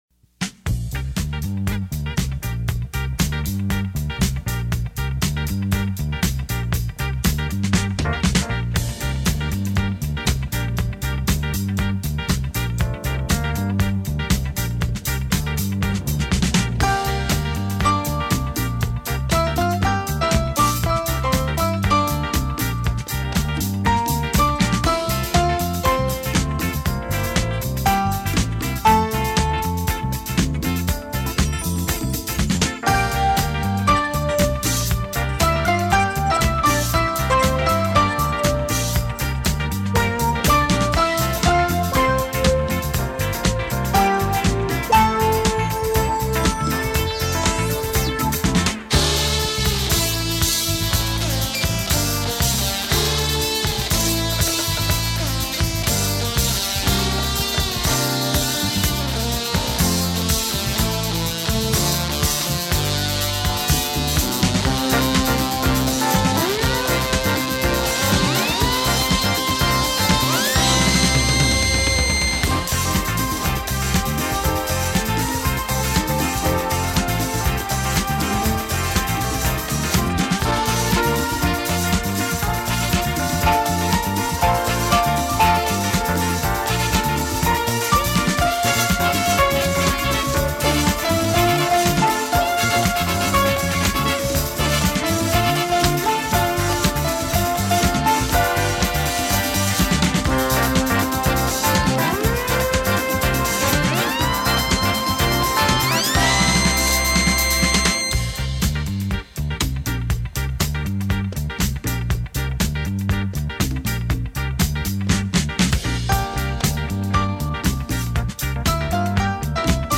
Gibson SG